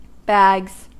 Ääntäminen
IPA : /bæɡz/